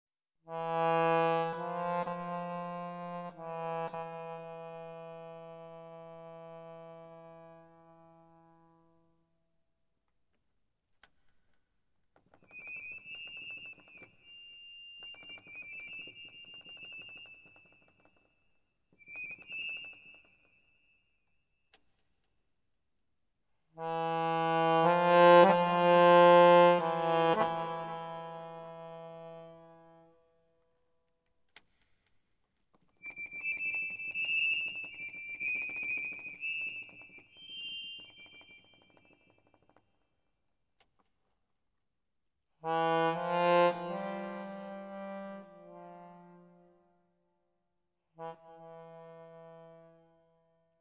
In this CD you find a completly improvised performance.